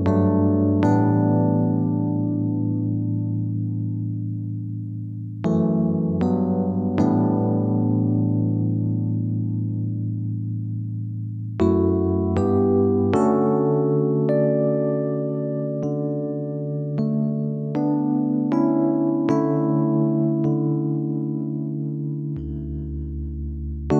R'n'B / Hip Hop